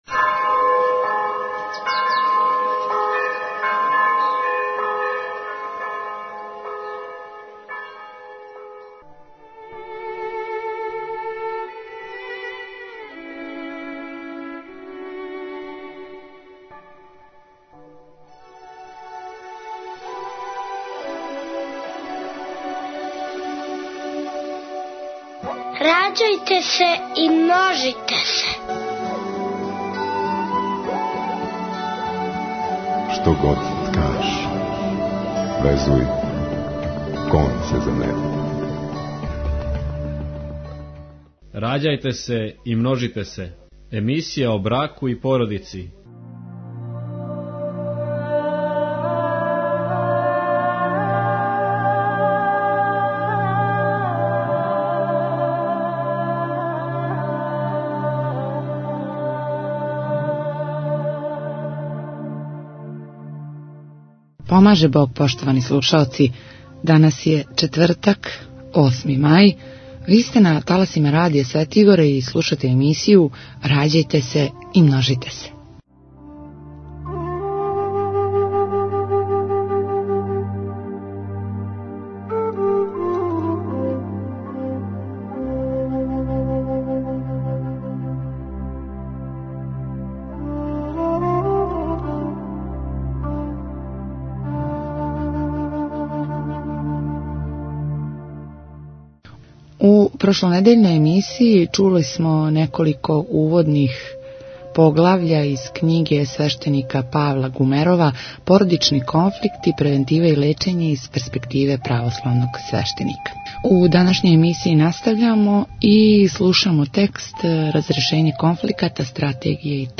У вечерашњој емисији имаћемо прилике да чујемо неколико интересантних поглавља из књиге у којима се говори о начинима разрешења породичног конфликта, о томе колико свакодневни стрес утиче на породични живот, као и о анксиозности као болести савременог човјека.